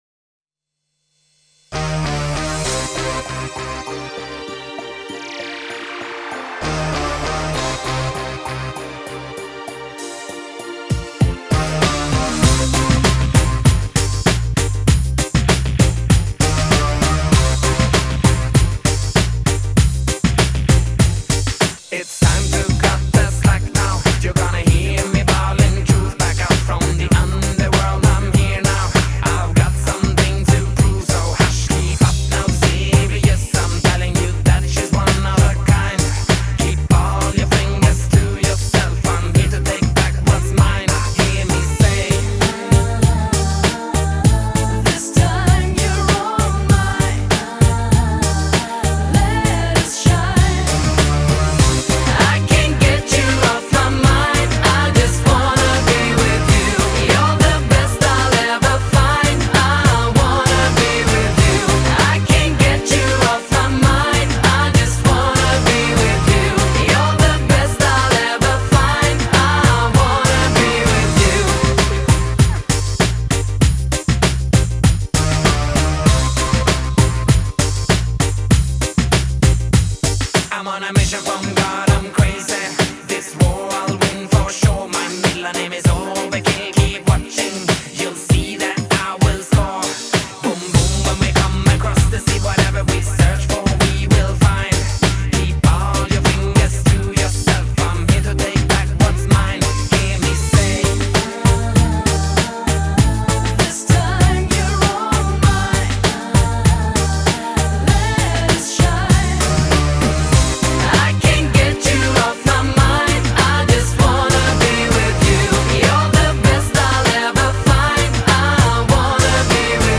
Жанр: Eurodance, Pop